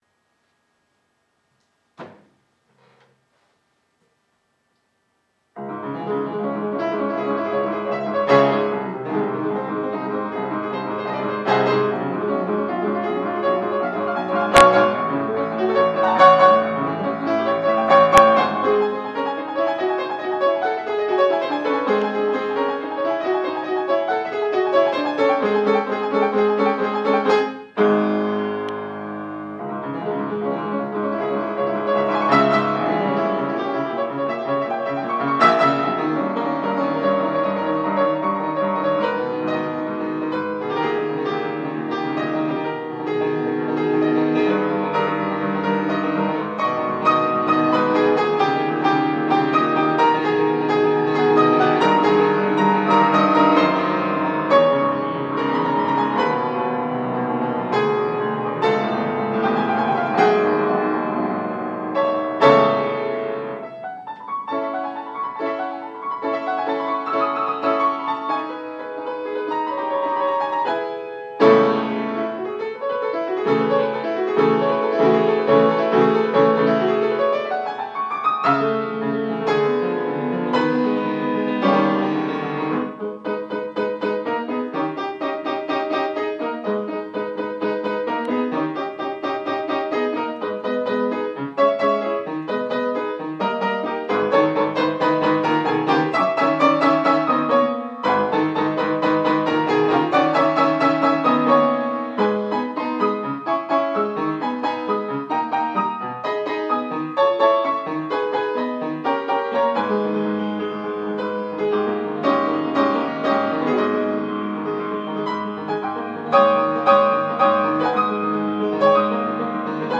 5'57, 4,1 Mo, ogg (serviette devant micro)